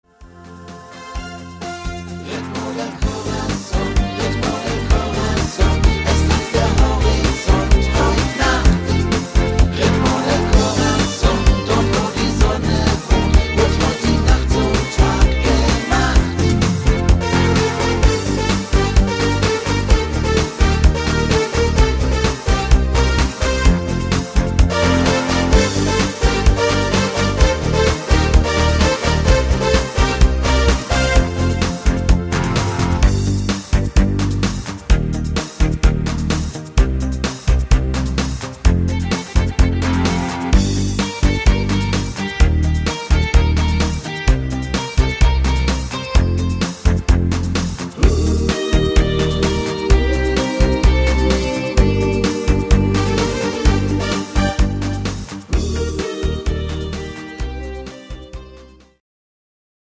Freestyle Song